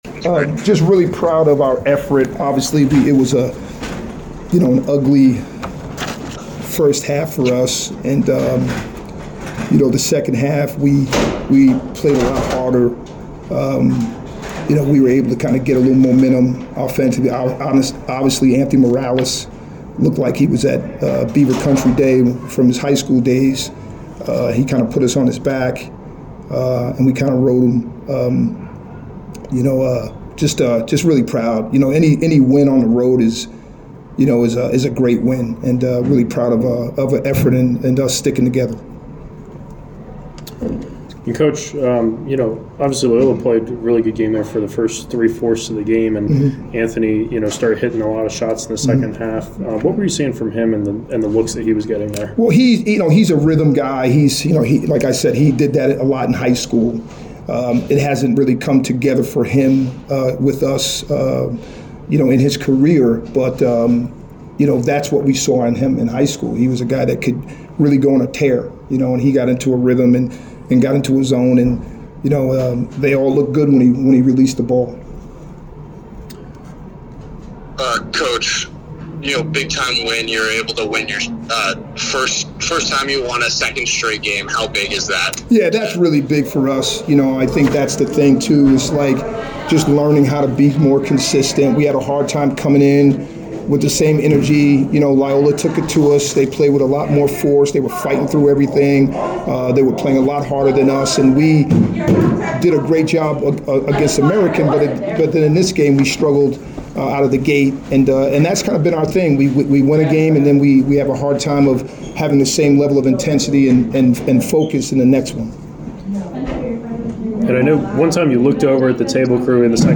Men's Basketball / Loyola Maryland Postgame Interview (1-13-24)